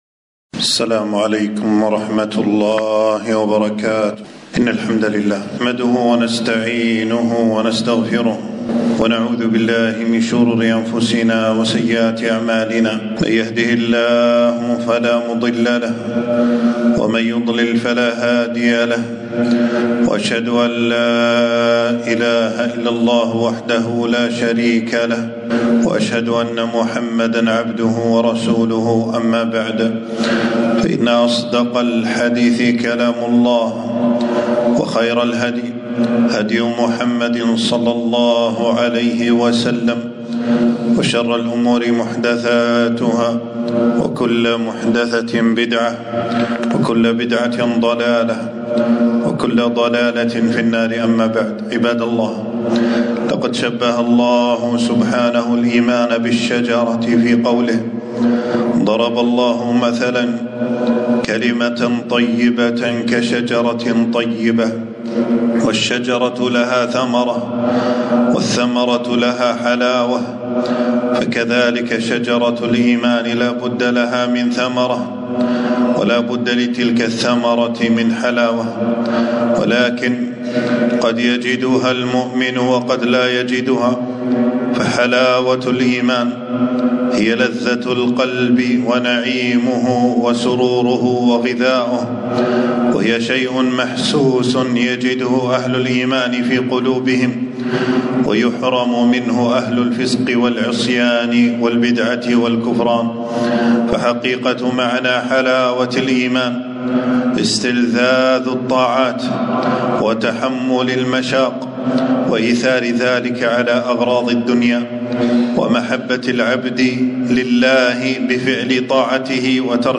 خطبة - كيف تذوق طعم الإيمان